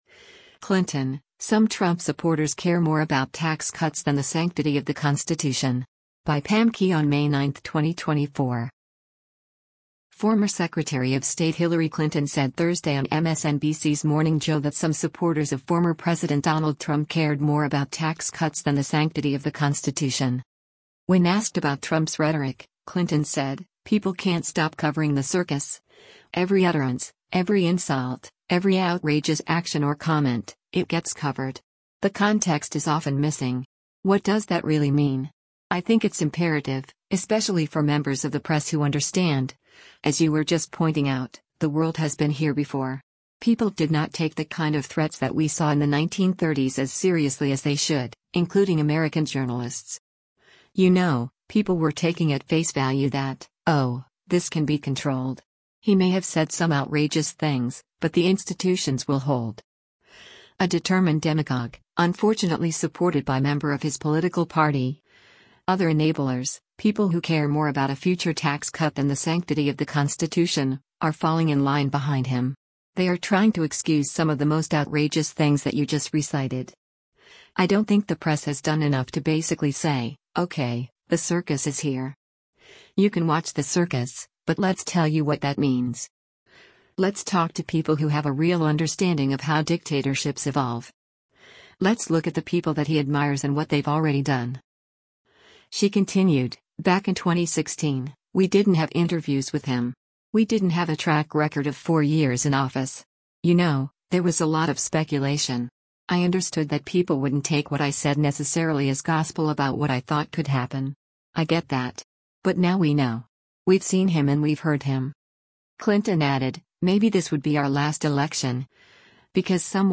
Former Secretary of State Hillary Clinton said Thursday on MSNBC’s “Morning Joe” that some supporters of former President Donald Trump cared more about tax cuts than the sanctity of the Constitution.